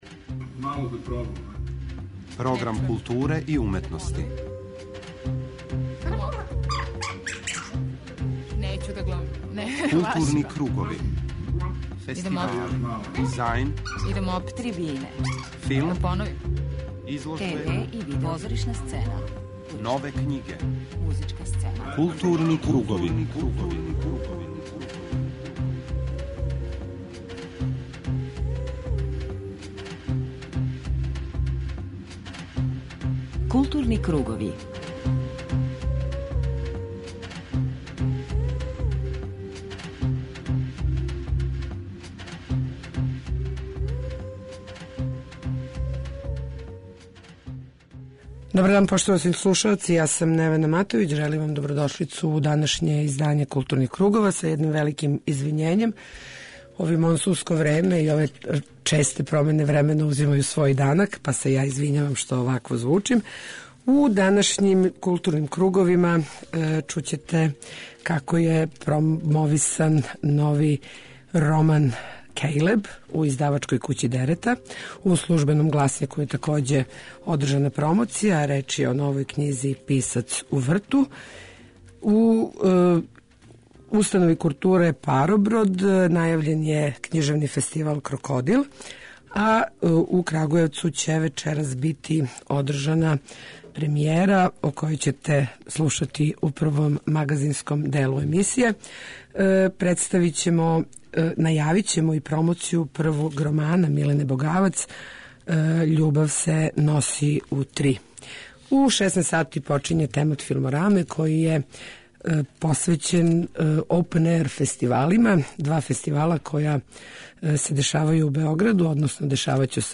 преузми : 41.29 MB Културни кругови Autor: Група аутора Централна културно-уметничка емисија Радио Београда 2.